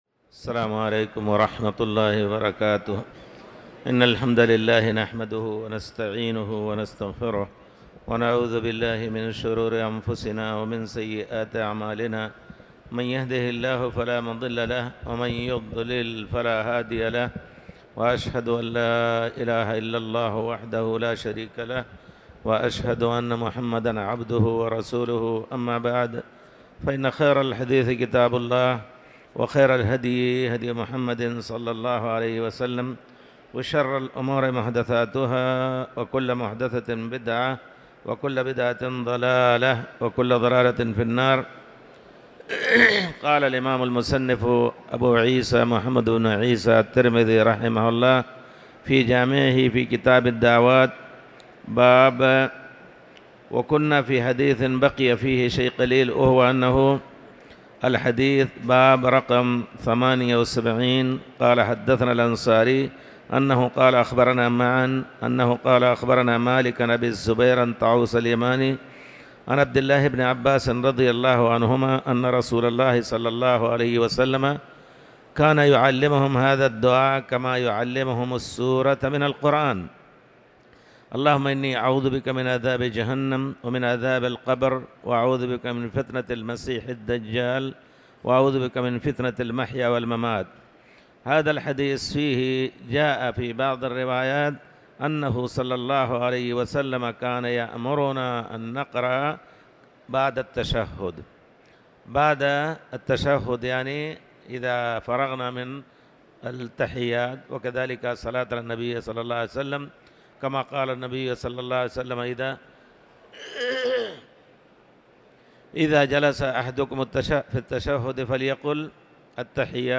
تاريخ النشر ٩ جمادى الآخرة ١٤٤٠ هـ المكان: المسجد الحرام الشيخ